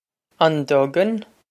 Pronunciation for how to say
On dug-in?
This is an approximate phonetic pronunciation of the phrase.